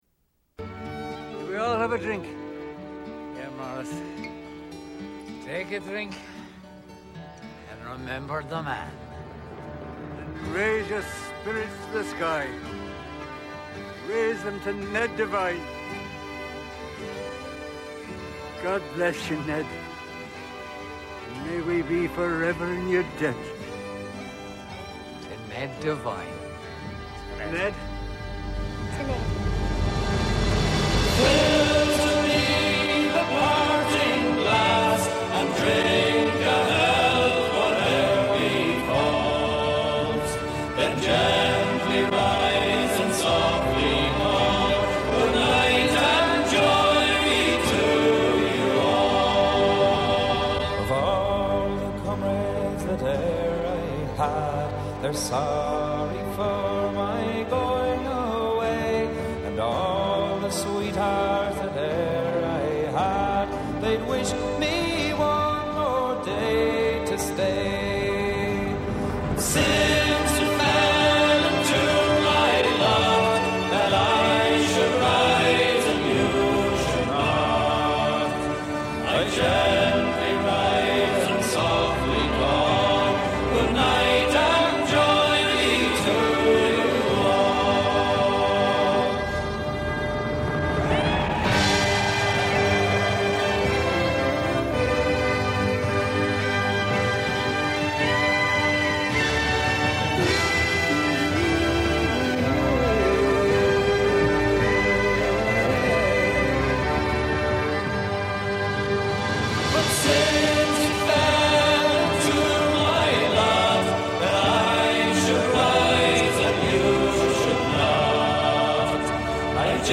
(a traditional Irish Folksong)